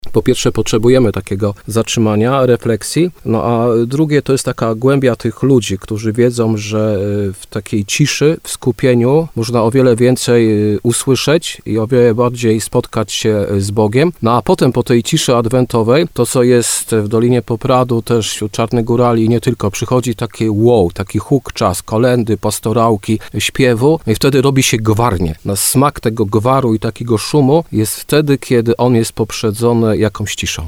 – My w dolinie Popradu absolutnie tego nie zmieniamy – mówił kapłan w programie Słowo za Słowo w radiu RDN Nowy Sącz.